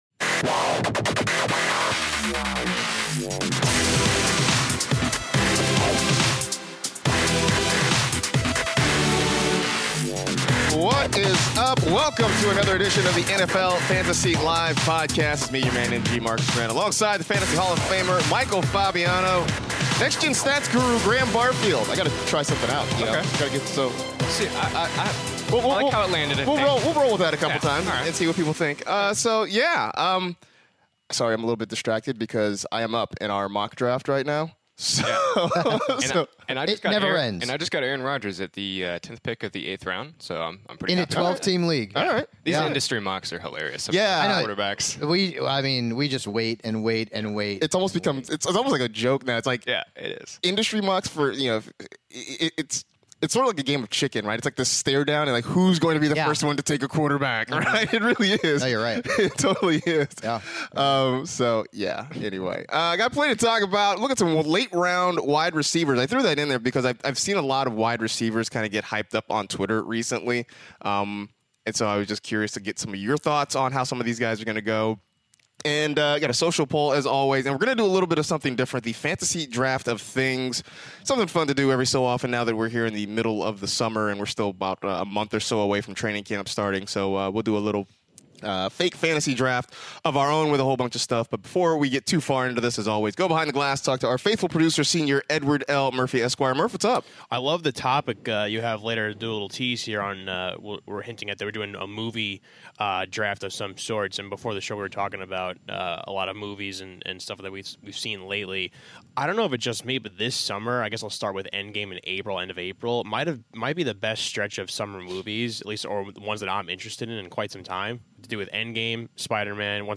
are back in studio for a brand new Fantasy Live Podcast! The guys start off with the biggest news of the week like Matthew Stafford reportedly played 2018 with a broken back and Amari Cooper saying his goal is 2,000 yards this season (3:10).